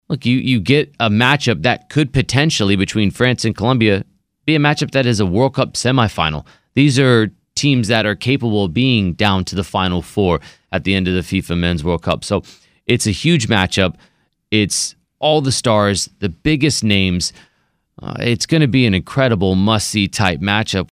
Soccer broadcaster